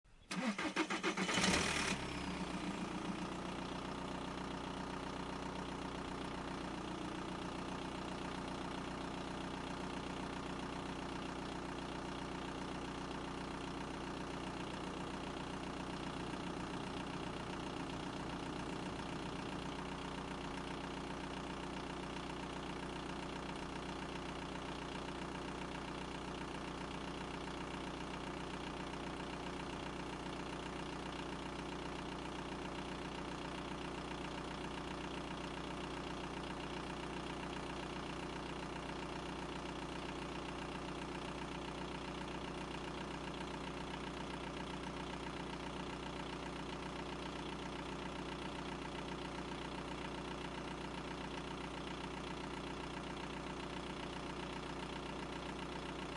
Download Car Engine sound effect for free.
Car Engine